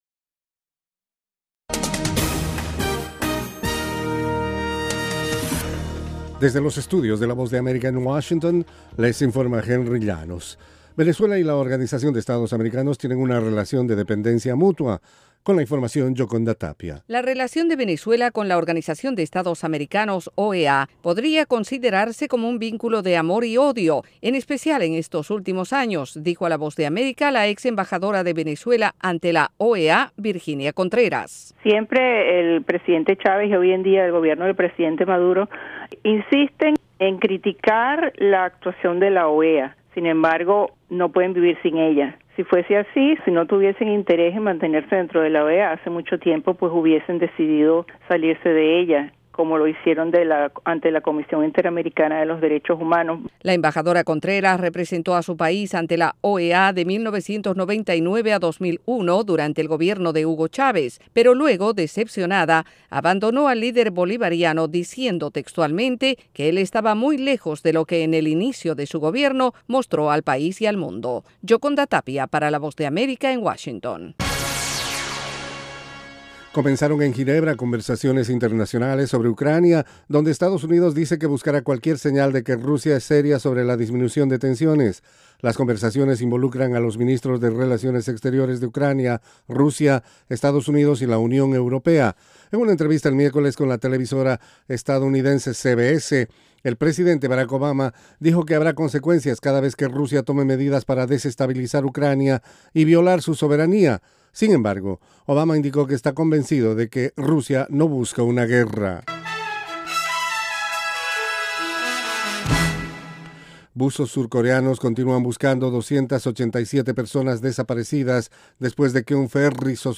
En cinco minutos de duración, Informativo VOASAT ofrece un servicio de noticias que se transmite vía satélite desde los estudios de la Voz de América.